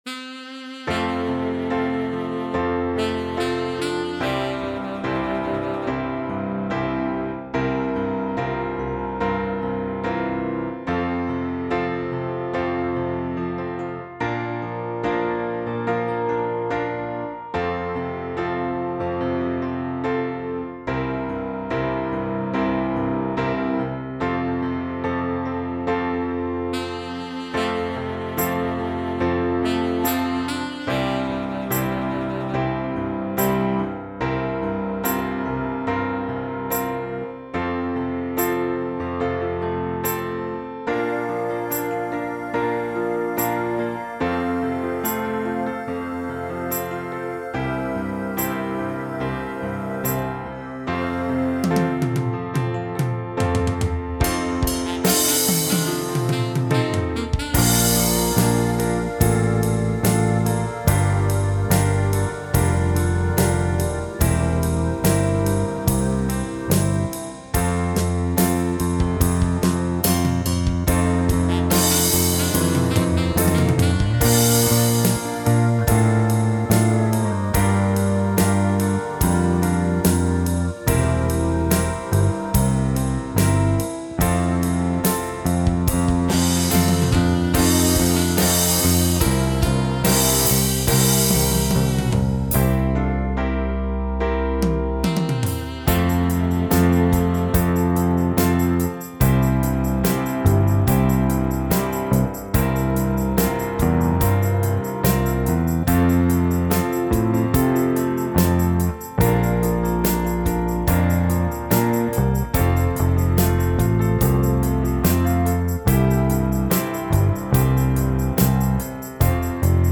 (Bass Guitar)